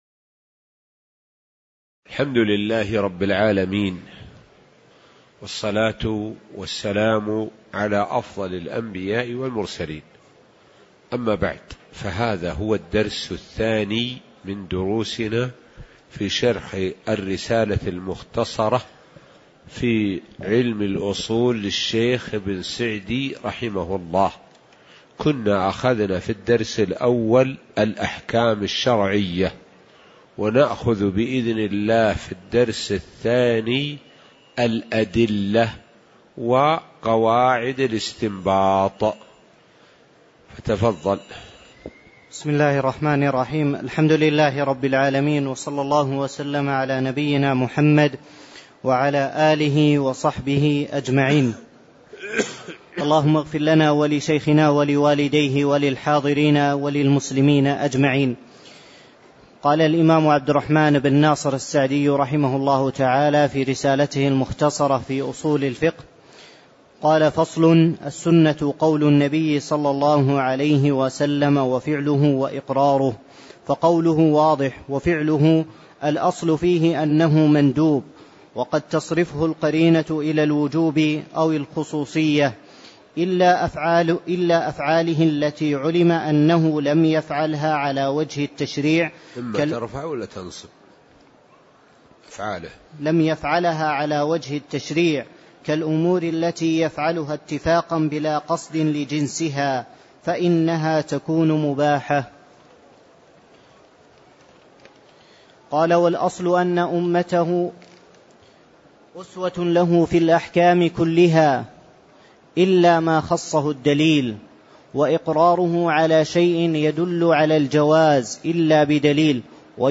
تاريخ النشر ٢٥ شوال ١٤٣٧ هـ المكان: المسجد النبوي الشيخ: معالي الشيخ د. سعد بن ناصر الشثري معالي الشيخ د. سعد بن ناصر الشثري الأدلة وقواعد الإستنباط (02)